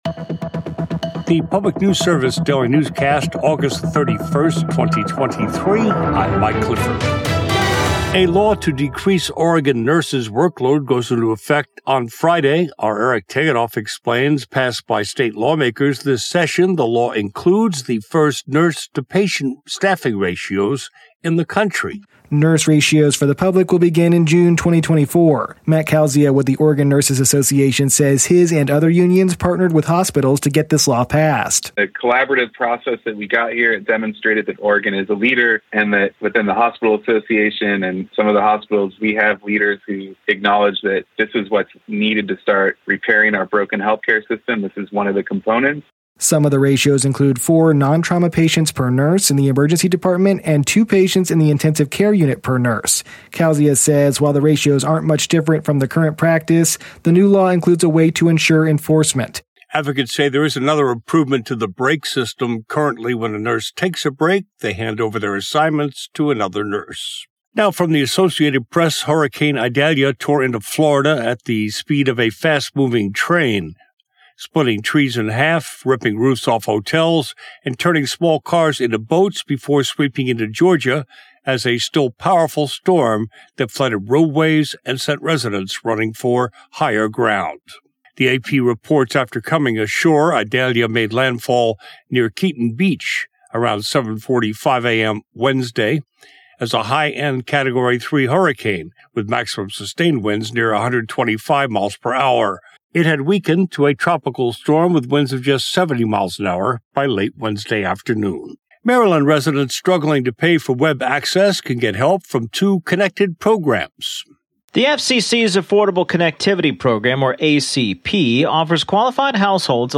Afternoon news update for Tuesday, January 13, 2026